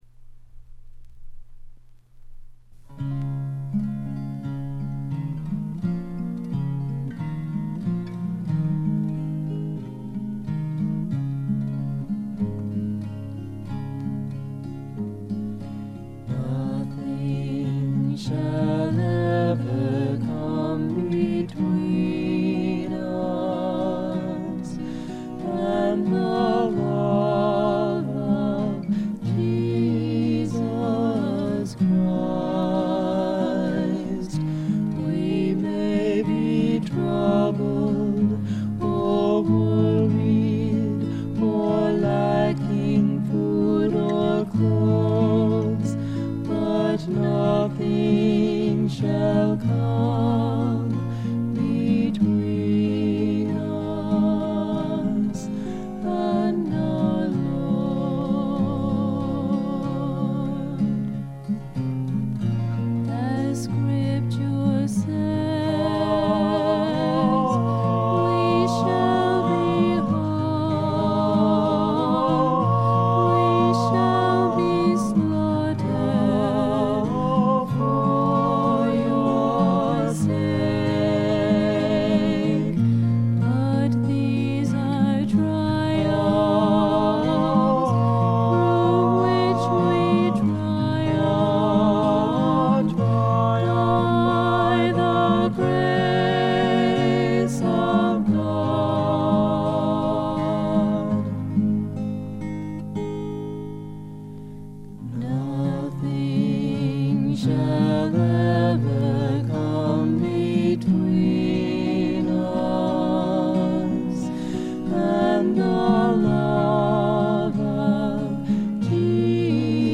5箇所ほどで散発的なプツ音。
ウィスコンシンのクリスチャン系フォーク・グループでたぶんこれが唯一作の自主制作盤だと思います。
男女3人づつの6人組で、ほとんどがオリジナル曲をやっています。
試聴曲は現品からの取り込み音源です。